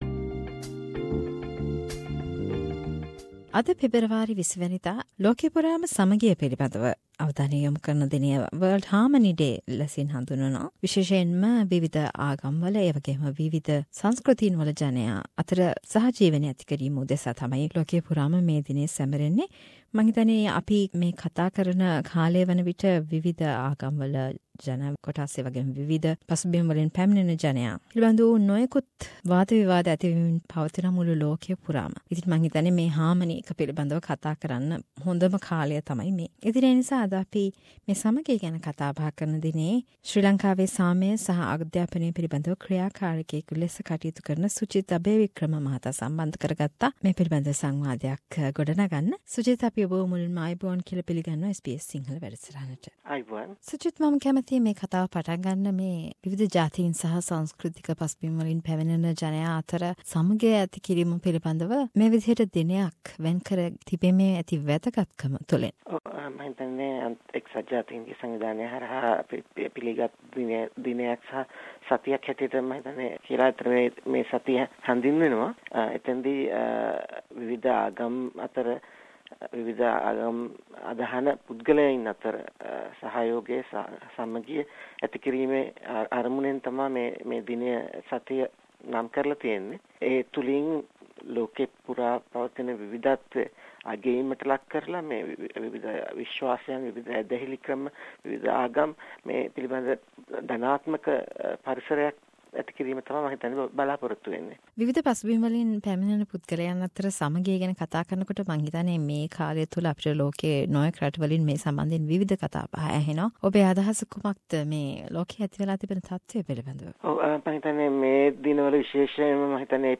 A discussion to mark World Harmony Day